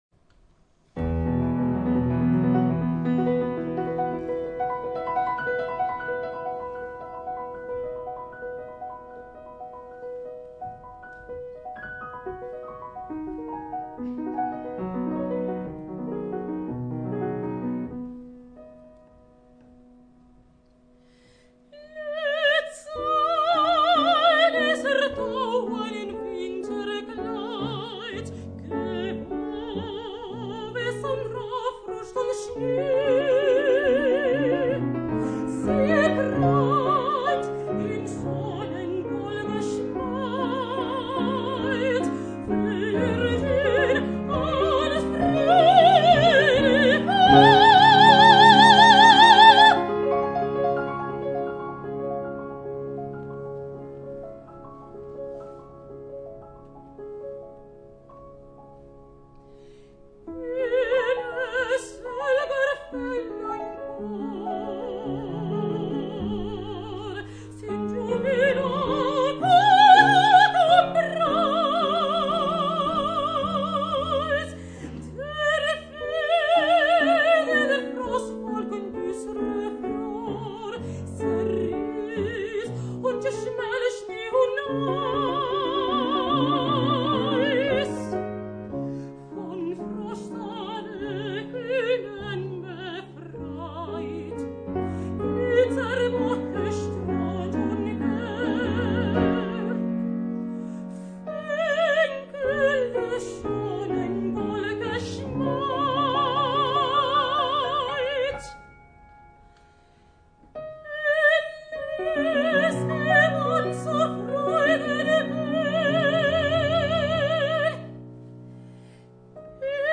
Música de cámara.
mezzosoprano
piano.